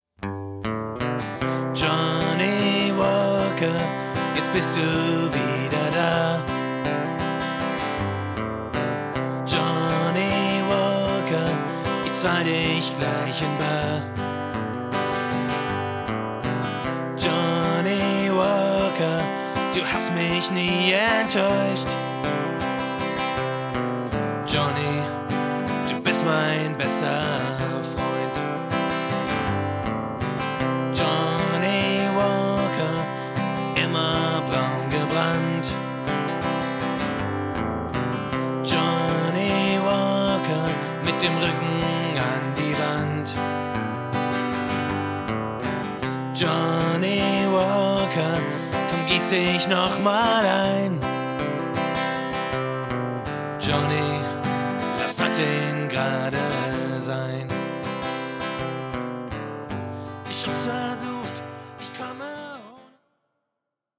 Rock & Pop Cover